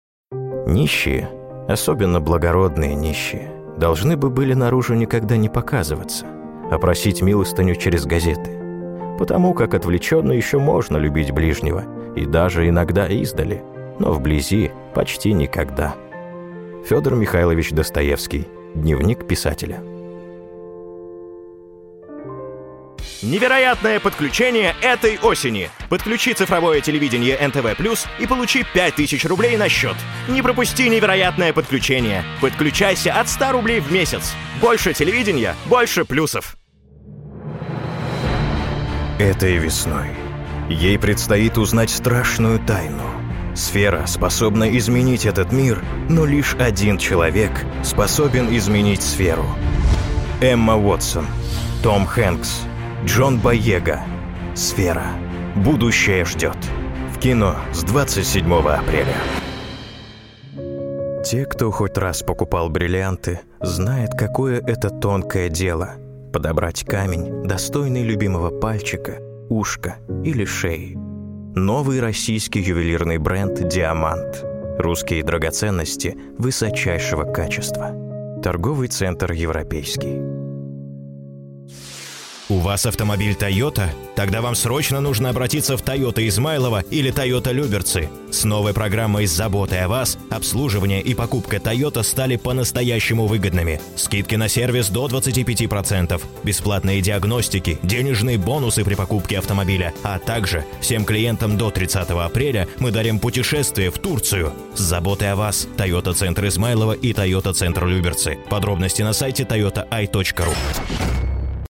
Микрофон: SE ELECTRONICS sE2200a II C; Аудиоинтерфейс: Steinberg UR 12; Подготовленное помещение.